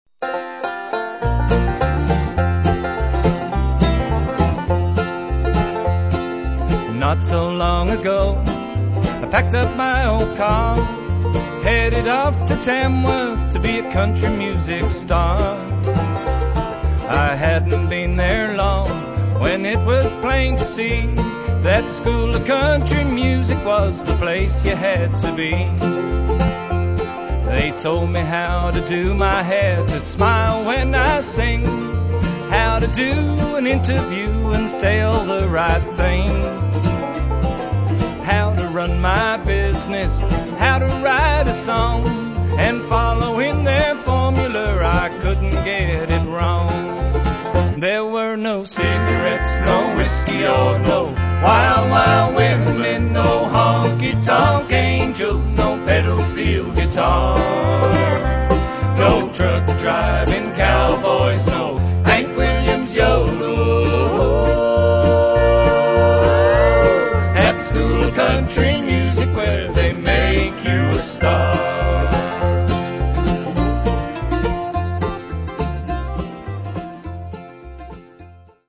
Double Bass, Vocals
Banjo, Vocals
hot bluegrass picking and soaring harmonies.